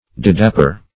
Didapper \Di"dap`per\, n. [For divedapper.